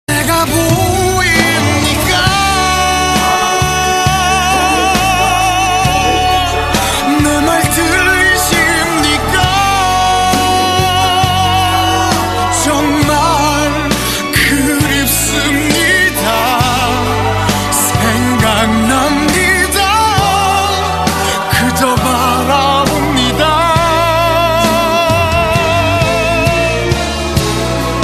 M4R铃声, MP3铃声, 日韩歌曲 68 首发日期：2018-05-15 09:01 星期二